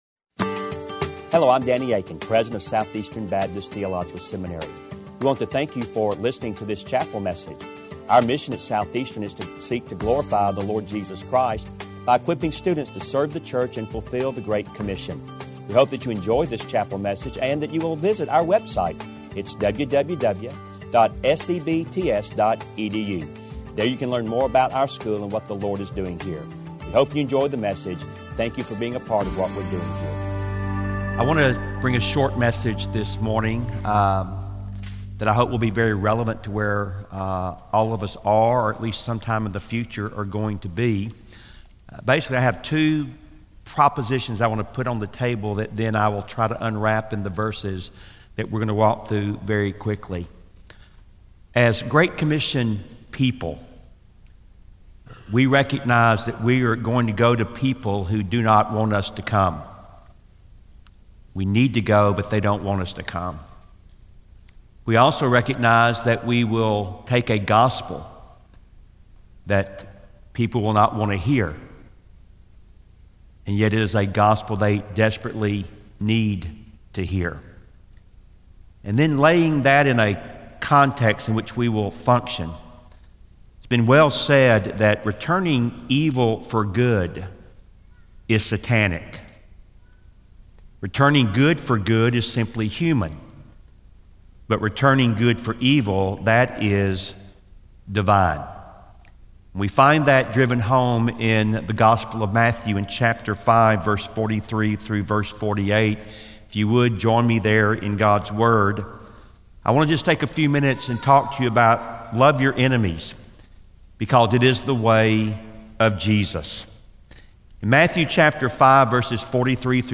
This sermon is from the final chapel of the Spring 2017 semester at Southeastern Seminary.